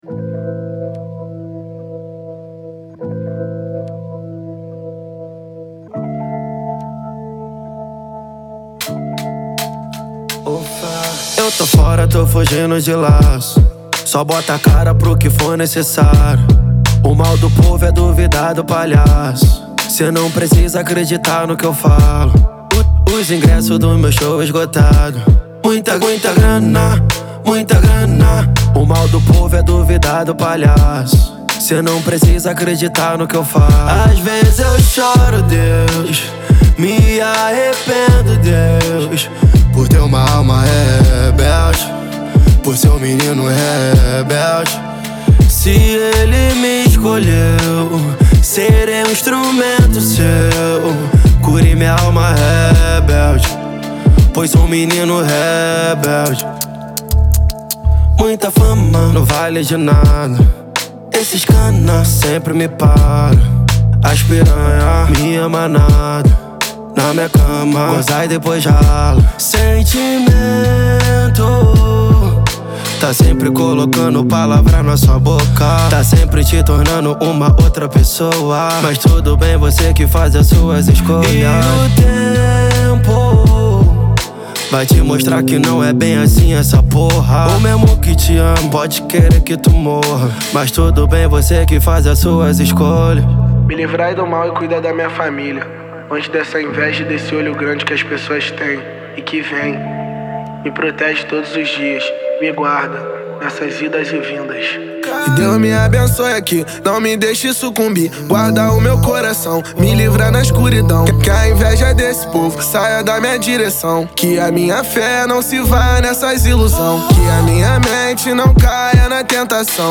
| Rap